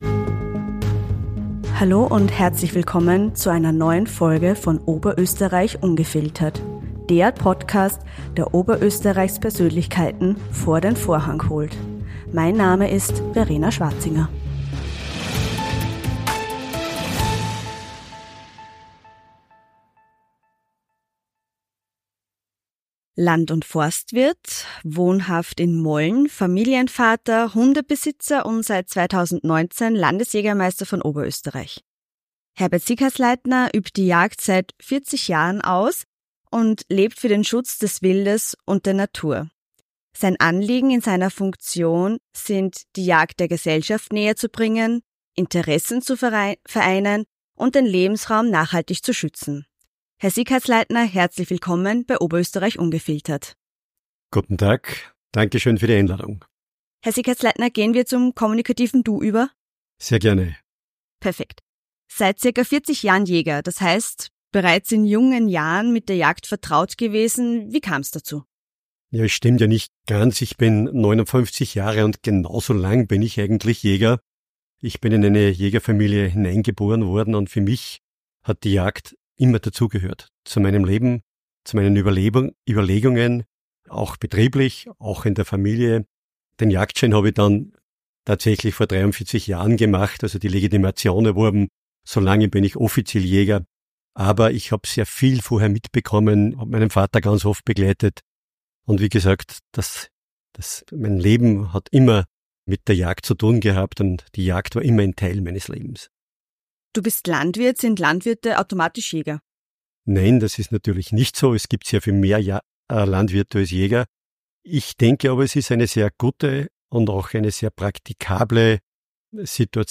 Das Interview geht auch auf aktuelle gesellschaftliche Themen ein, wie die Zunahme an Radfahrern und Wanderern in den Jagdgebieten sowie den Umgang mit kontroversen Wildtieren wie dem Wolf und dem Biber.